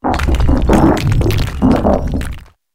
garganacl_ambient.ogg